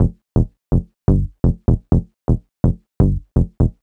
cch_bass_jupiter_125_F.wav